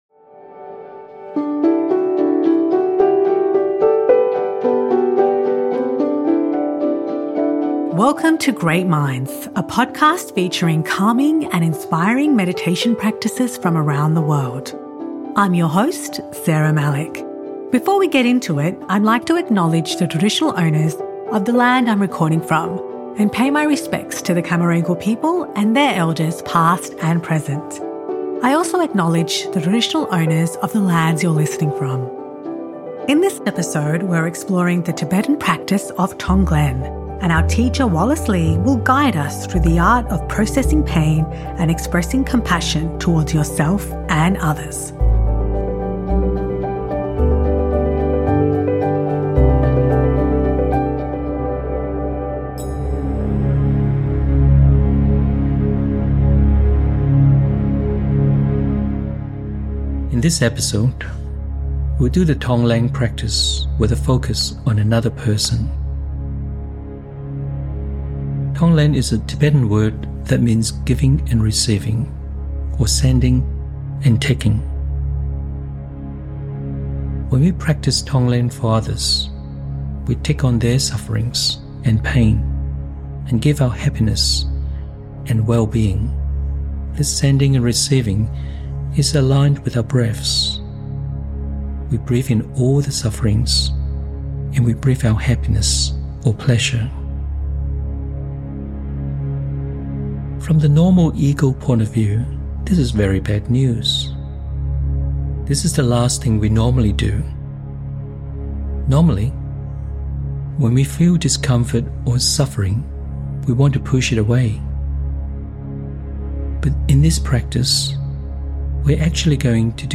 Great Minds is a podcast from SBS Audio that guides you through different meditation styles from around the world.